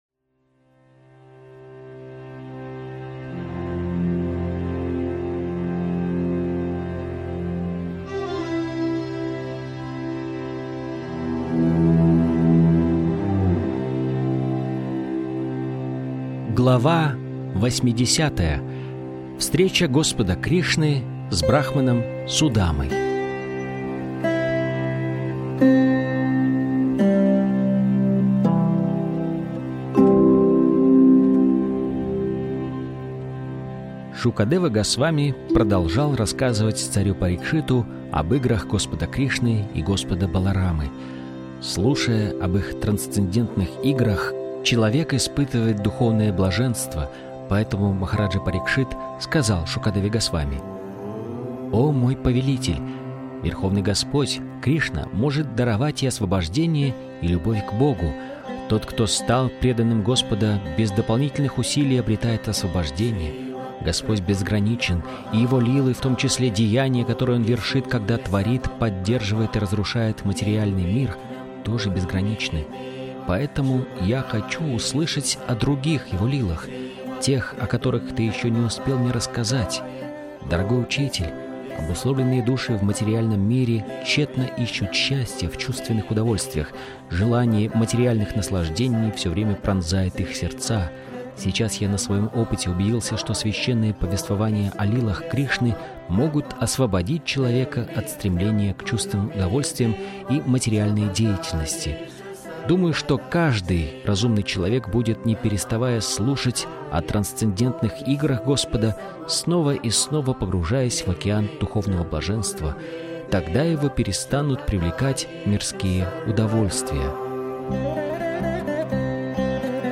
Источник вечного наслаждения автор Абхай Чаран Де Бхактиведанта Свами Прабхупада Информация о треке Автор аудиокниги : Абхай Чаран Де Бхактиведанта Свами Прабхупада Аудиокнига : Кришна.